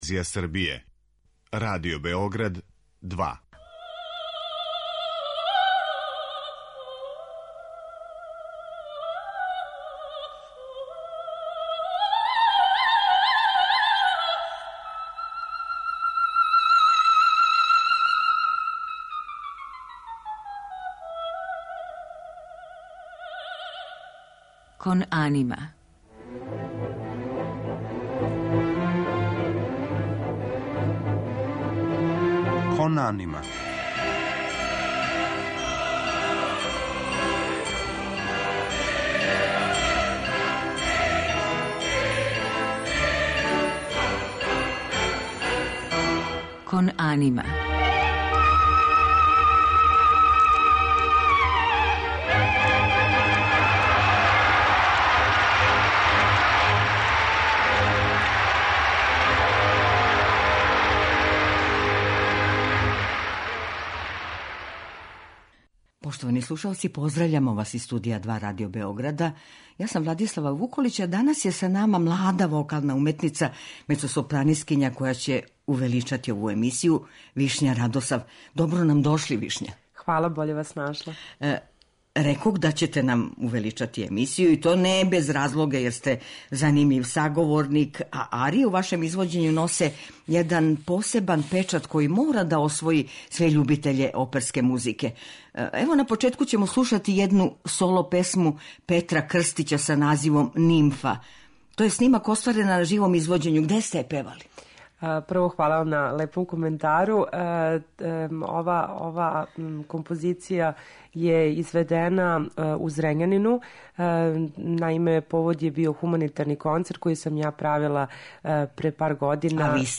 У музичком делу емисије биће емитоване арије Франческа Чилеа, Волфганга Амадеуса Моцарта, Ђузепа Вердија и домаћих композитора, у извођењу наше данашње гошће.